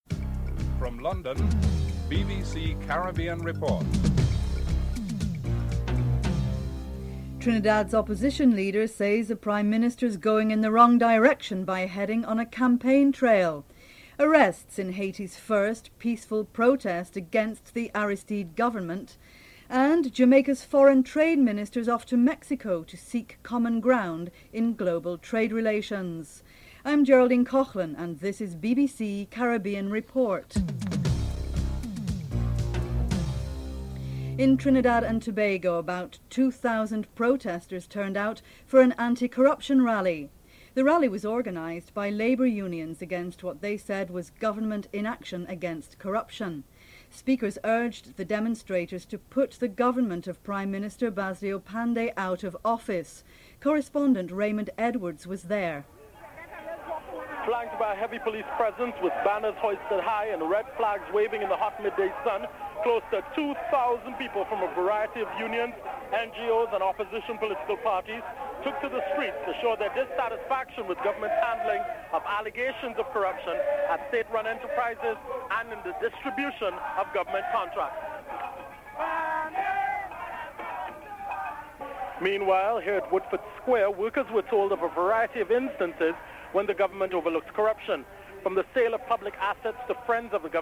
The British Broadcasting Corporation
1. Headlines (00:00-00:32)